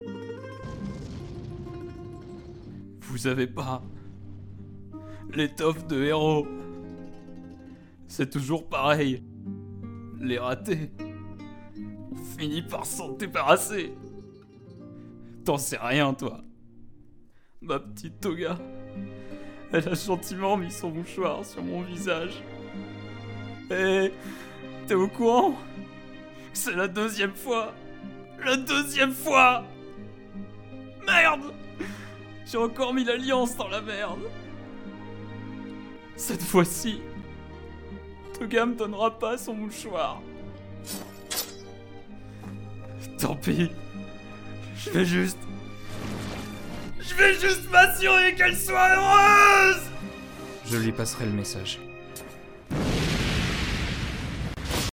Extrait Vidéo Narration Pkm
12 - 30 ans - Baryton Ténor